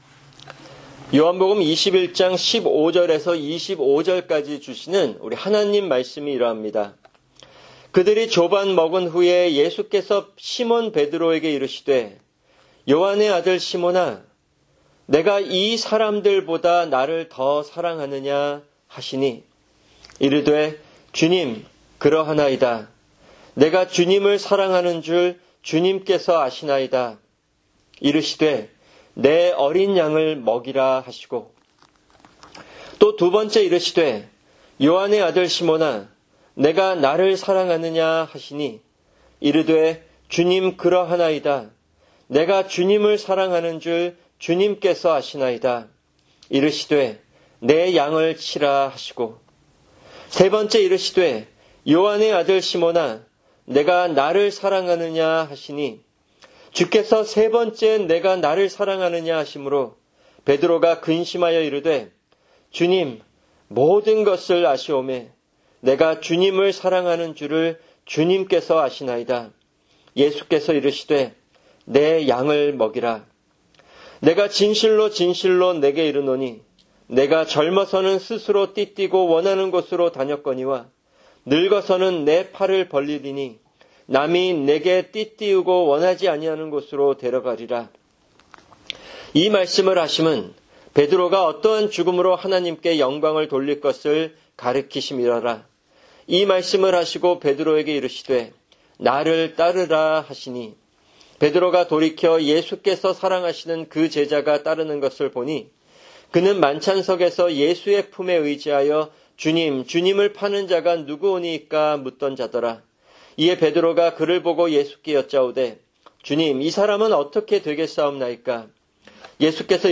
[주일 설교] 요한복음 15:1-11
[English Audio Translation] John 15:1-11